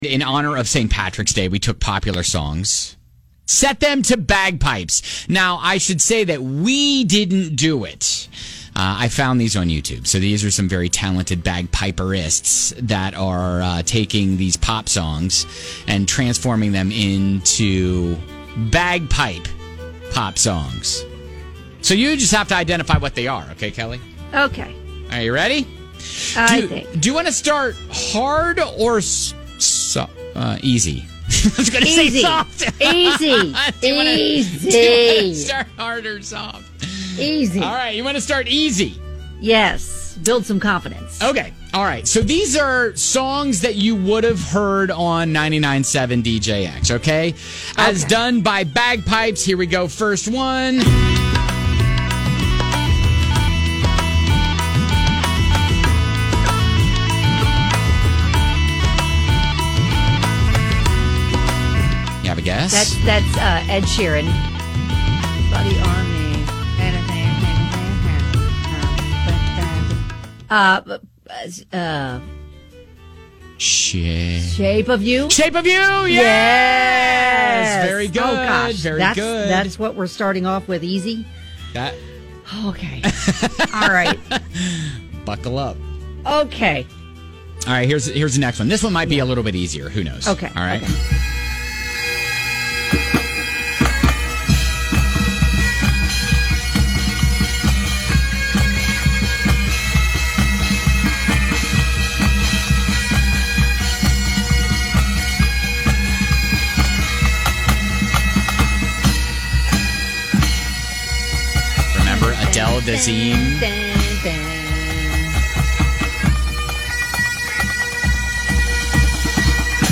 In honor of St. Patrick’s Day, we took popular songs and set them to bagpipes… try to identify the song!